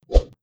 Close Combat Swing Sound 75.wav